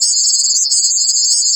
SFX06   01-R.wav